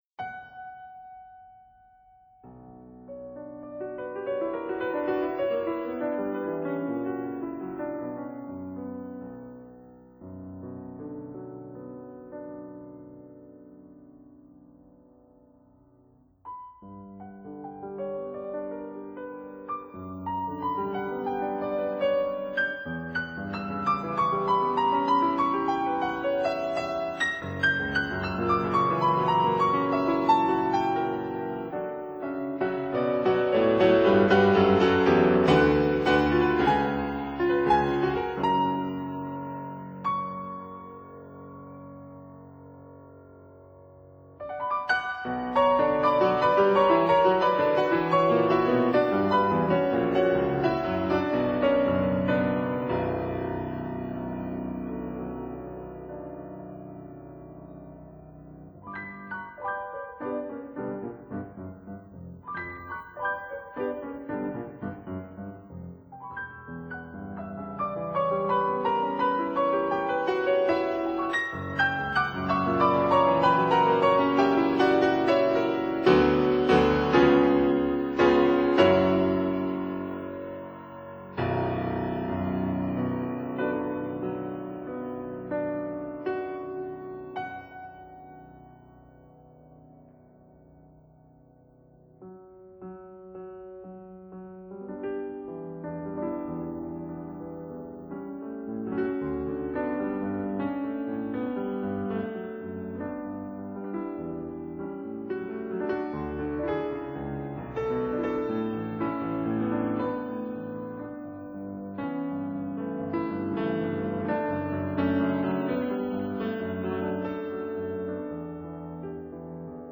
piano Date